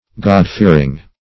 God-fearing \God"-fear`ing\, a.